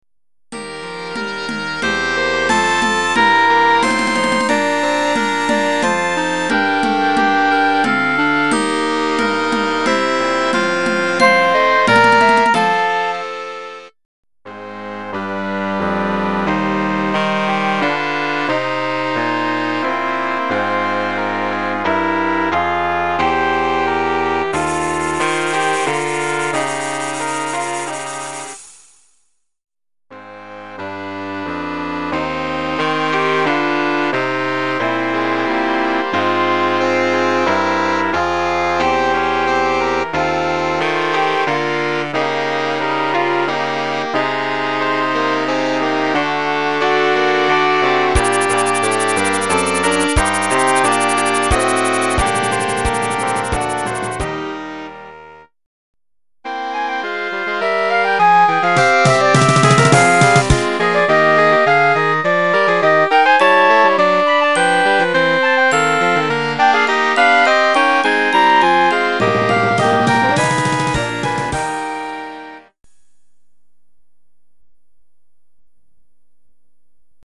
" Antiche Danze "   -    Brano originale per banda in 3 movimenti